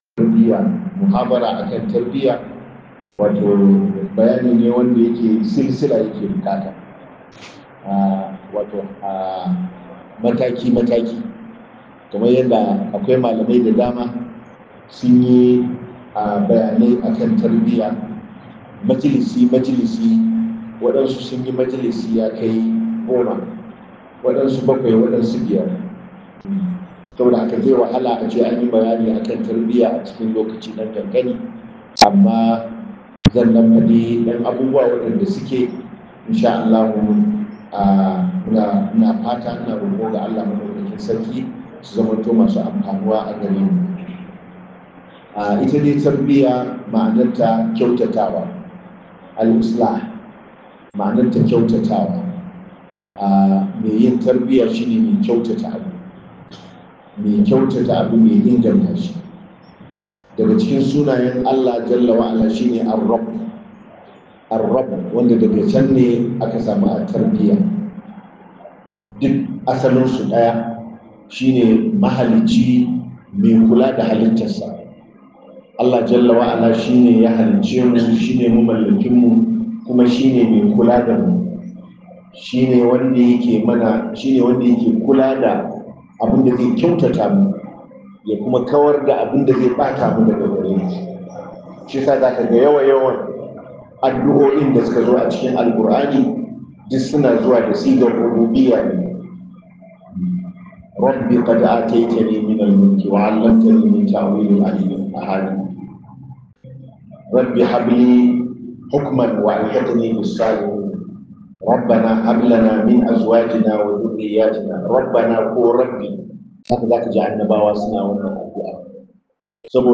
MUHADARA